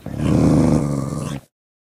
wolf_growl1.ogg